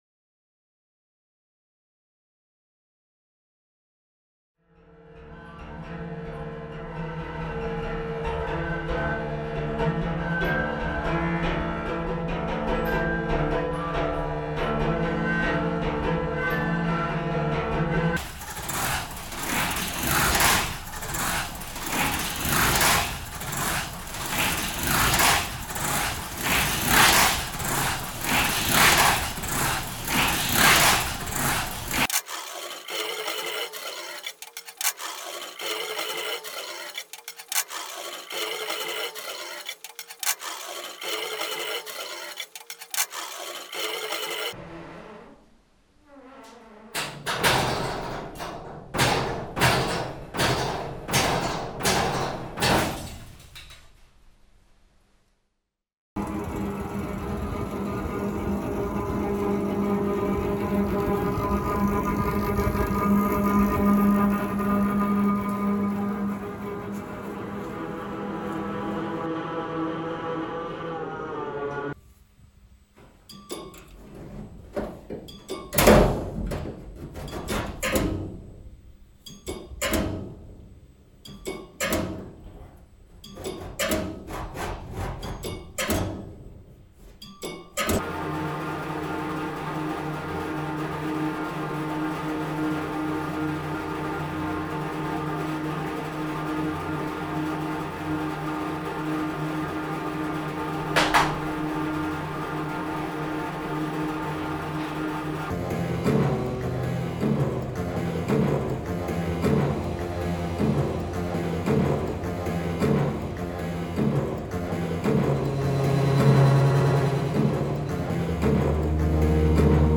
For Manoir Bruit, he used the façade of the Manoir Bruit as a sound box.